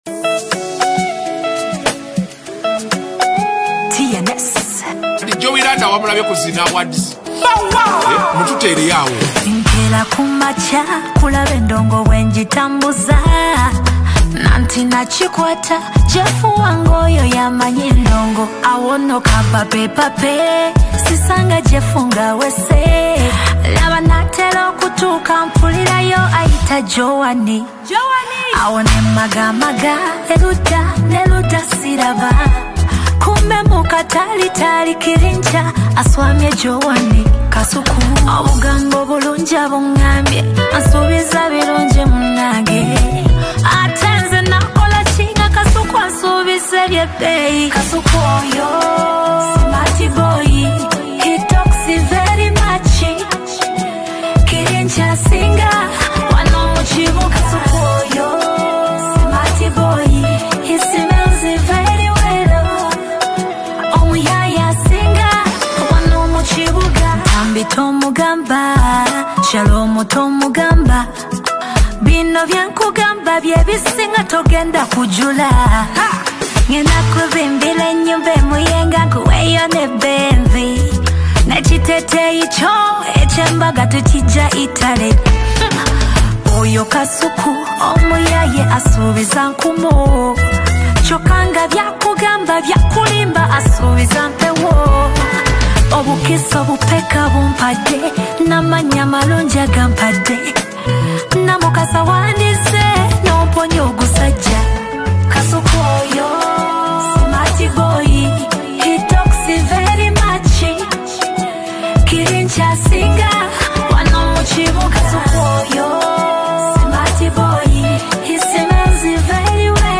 With her strong voice and emotional delivery